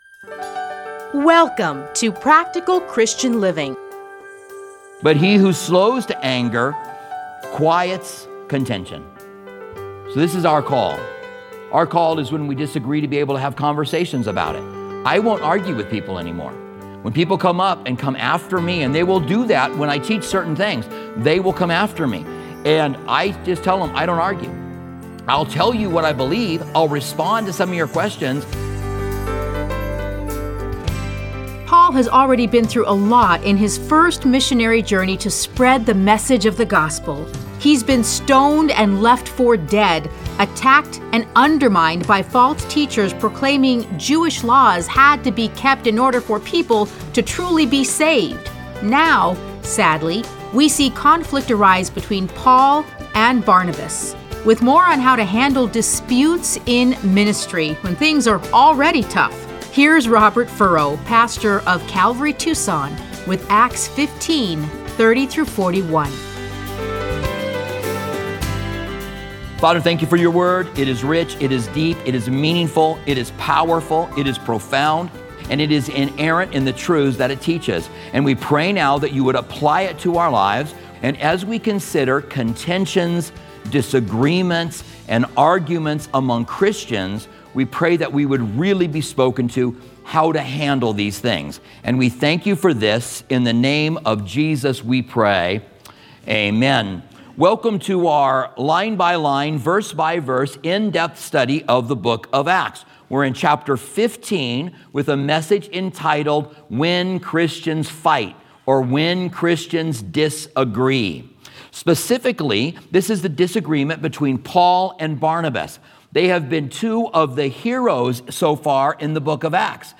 Listen to a teaching from Acts 15:30-41.